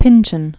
(pĭnchən), Thomas Ruggles Born 1937.